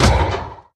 Minecraft Version Minecraft Version snapshot Latest Release | Latest Snapshot snapshot / assets / minecraft / sounds / mob / irongolem / hit3.ogg Compare With Compare With Latest Release | Latest Snapshot